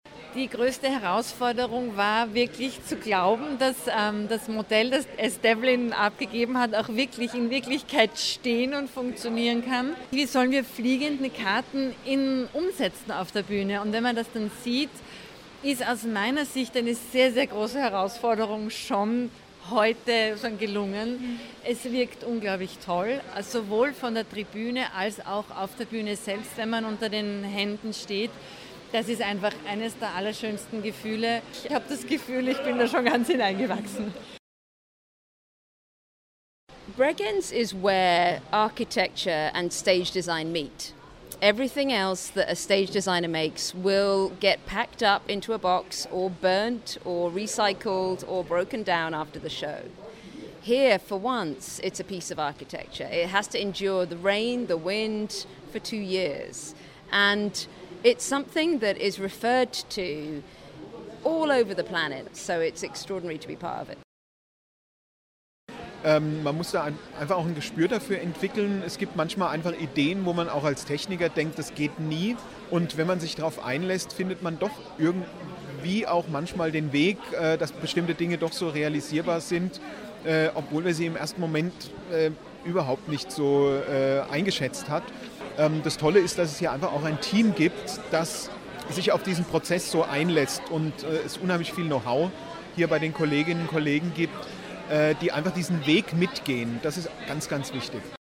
O-Ton Richtfest 2017
bregenz_richtfest-carmen-beitrag.mp3